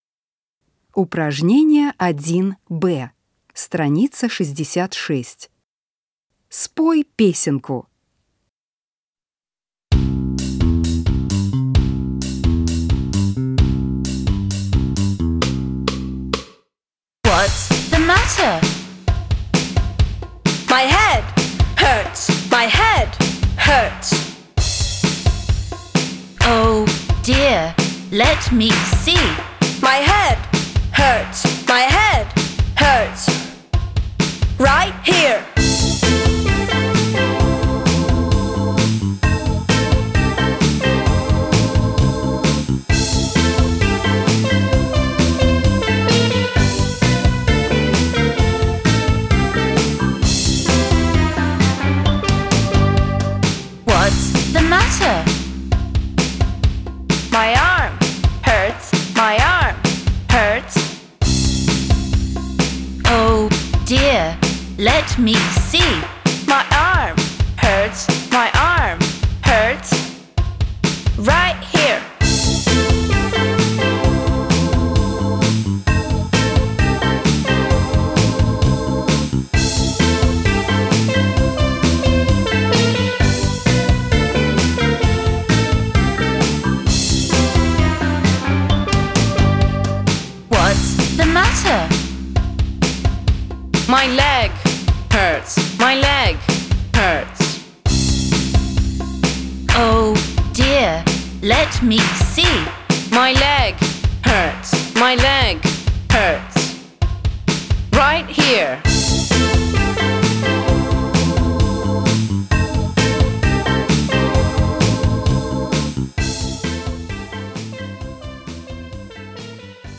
Спой песенку.